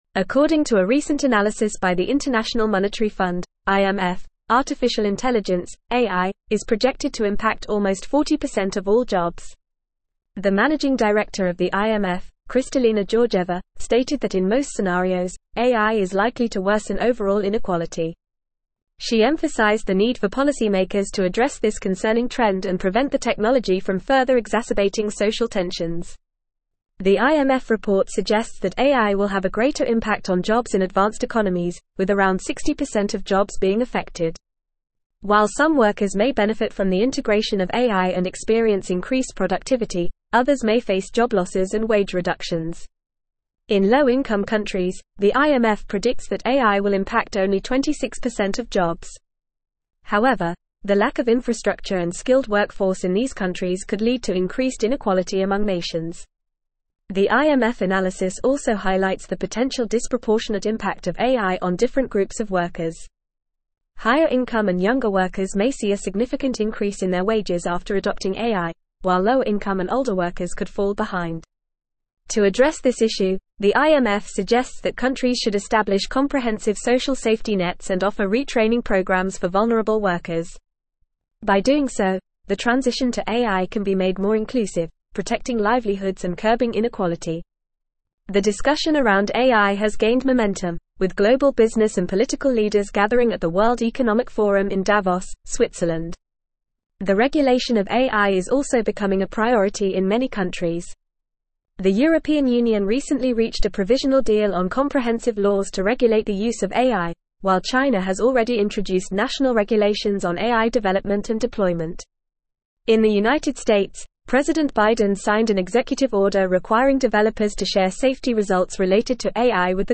English-Newsroom-Advanced-FAST-Reading-AIs-Impact-on-Jobs-and-Inequality-IMF-Analysis.mp3